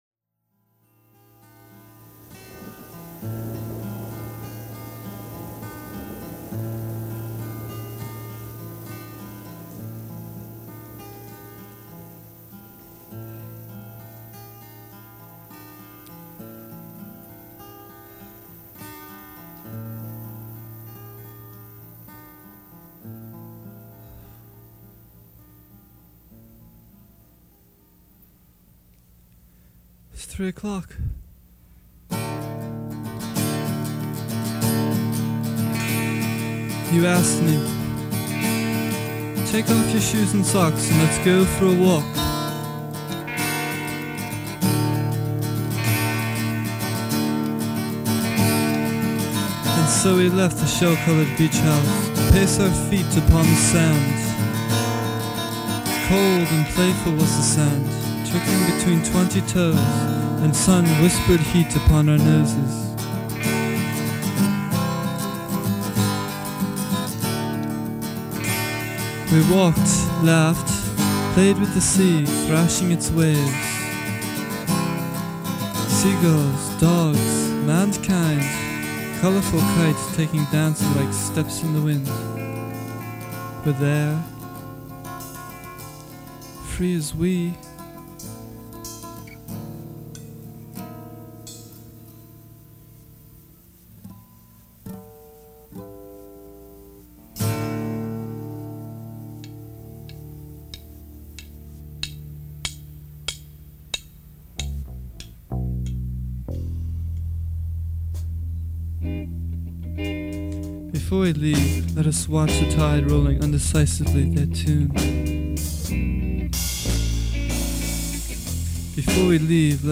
poetic, instrumental tracks